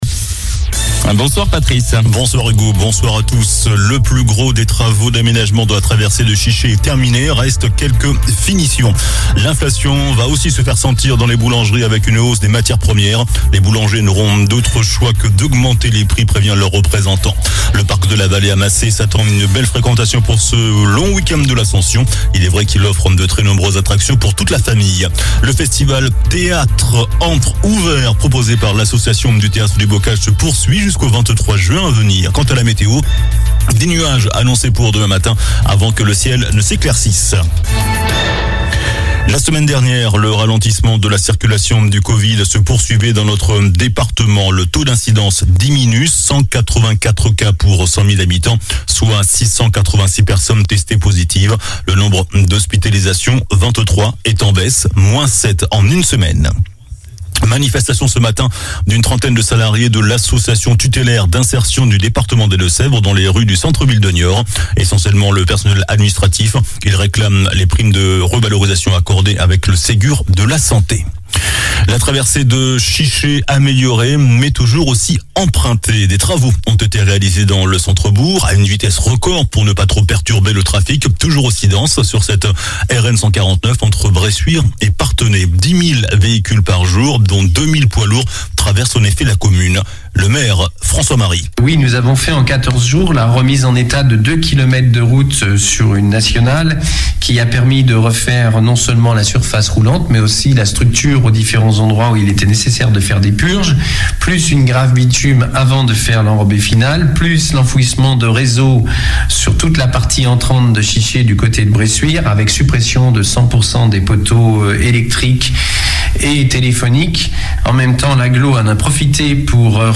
JOURNAL DU MERCREDI 25 MAI ( SOIR )